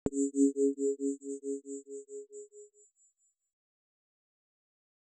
tone1.L.wav